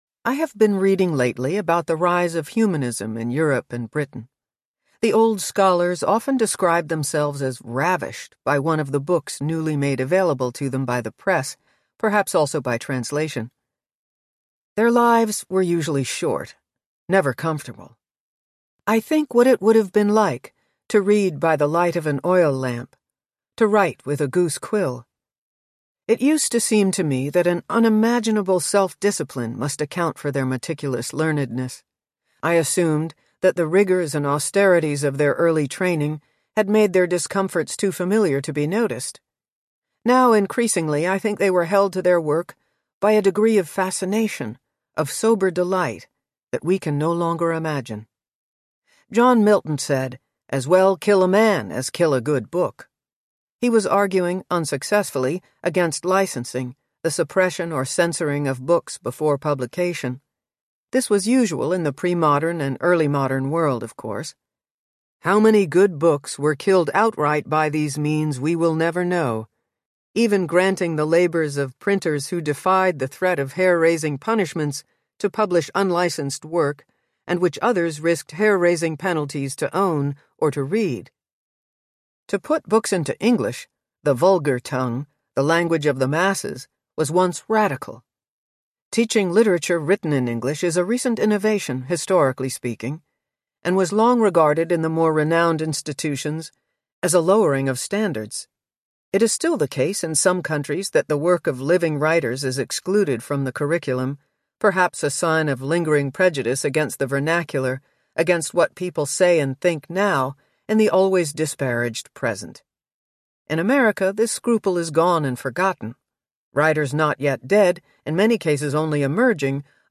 What Are We Doing Here? Audiobook
11.1 Hrs. – Unabridged